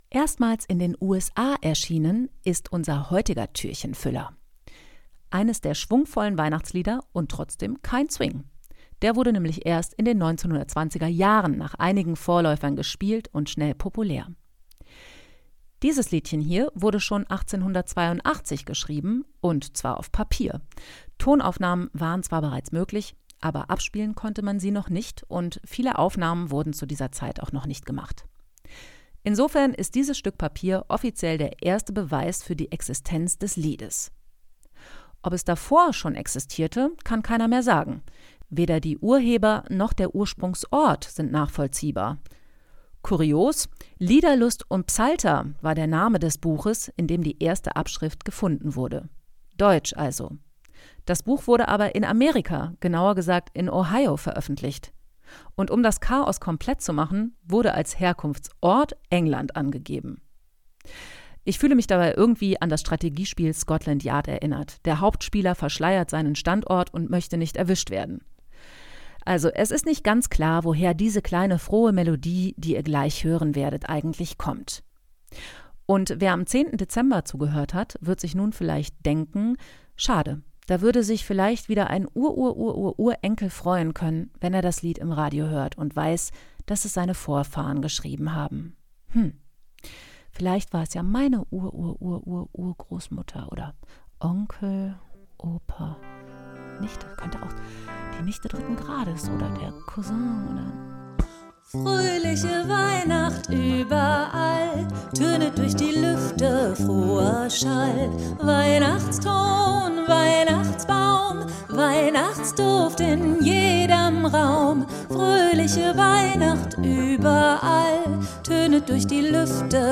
Eines der schwungvollen Weihnachtslieder und trotzdem kein Swing.
Es ist also nicht ganz klar, woher diese kleine frohe Melodie die Ihr gleich hören werdet eigentlich kommt.